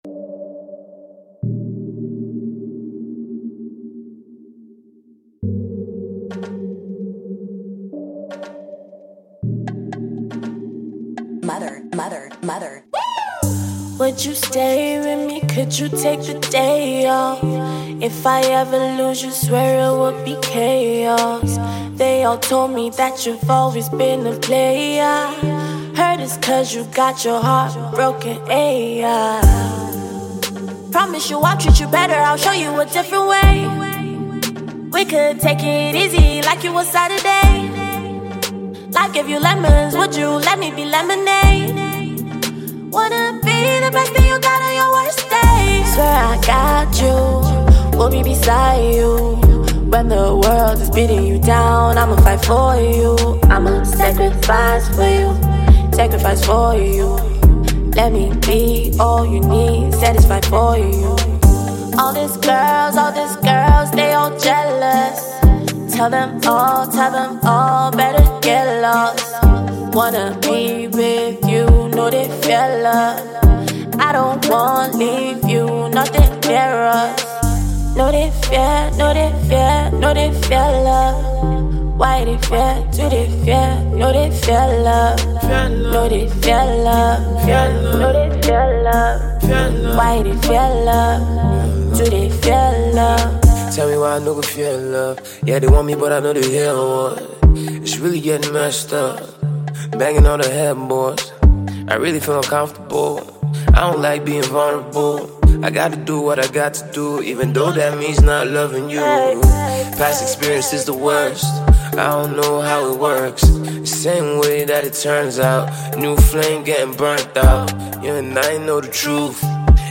R&B
hip-hop
a track that is as passionate as a burning flame